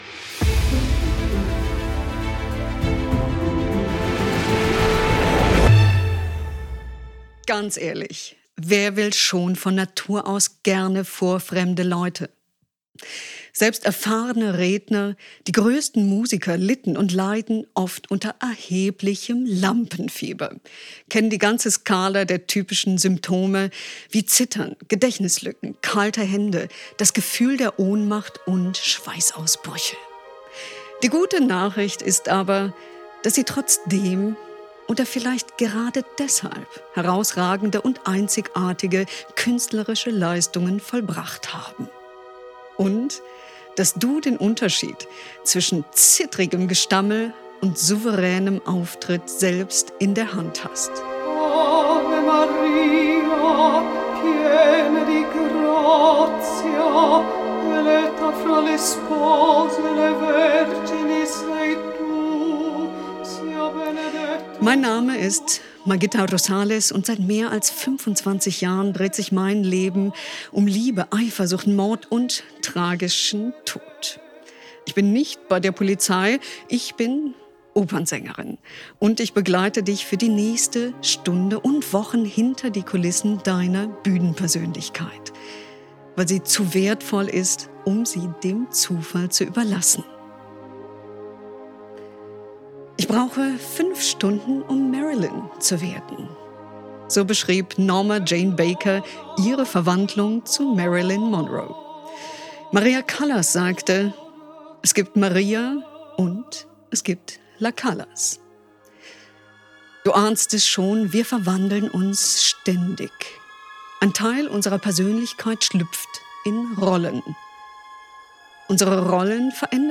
Coaching im Hörbuchformat